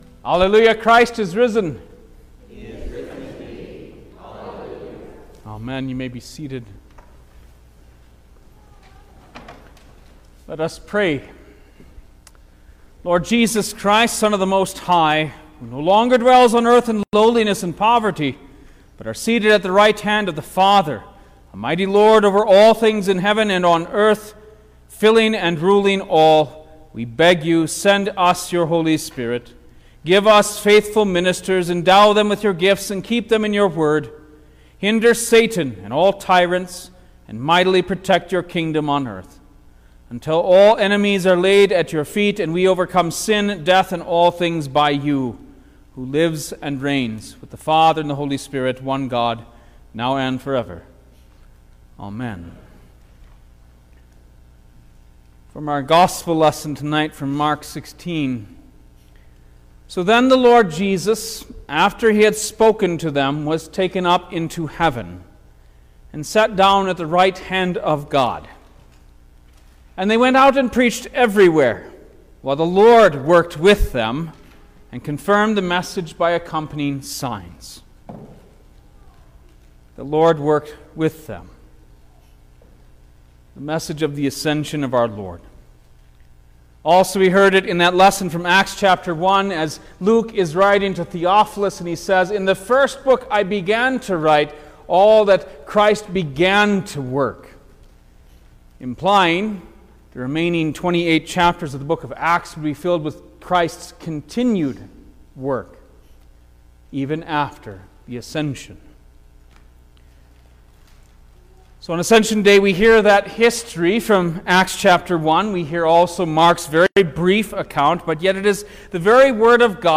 May-9_2024_Ascension-Day_Sermon-Stereo.mp3